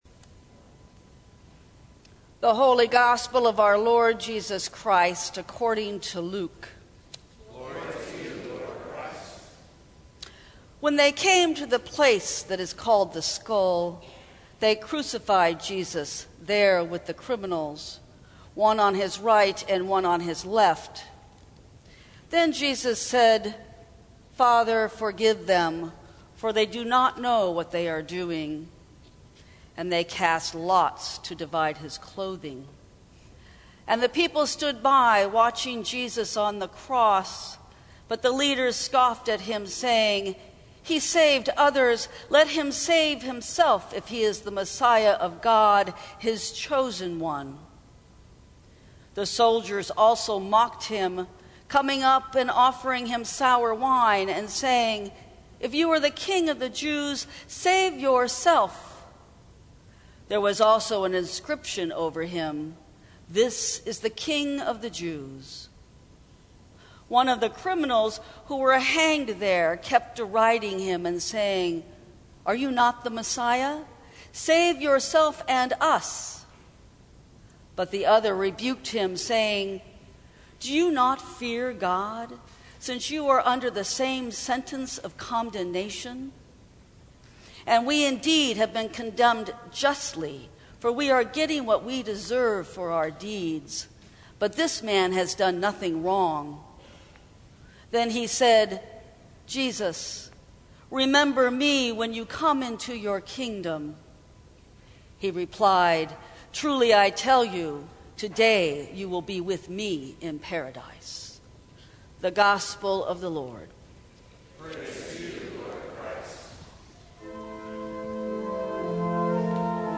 Sermons from St. Cross Episcopal Church The Beginning Nov 25 2019 | 00:13:49 1x 00:00 / 00:13:49 Subscribe Share Apple Podcasts Spotify Overcast RSS Feed Share Link Embed